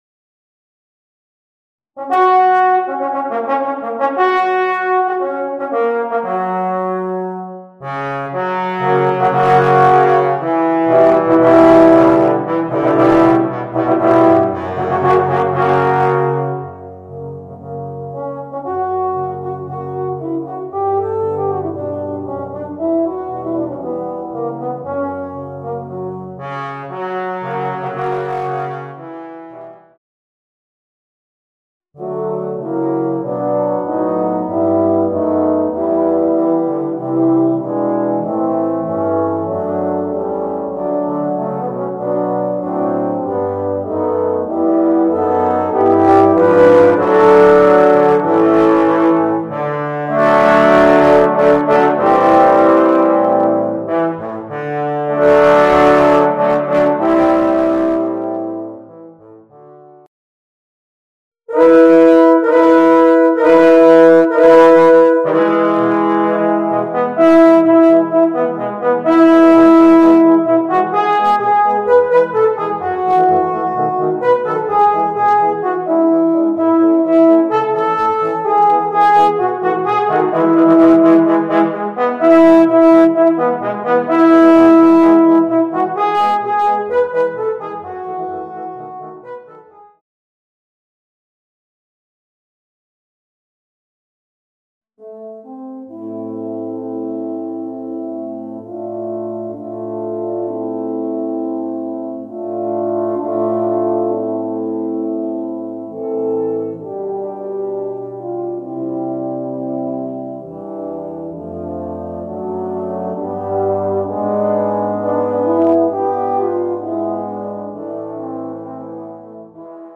Voicing: 4 Euphoniums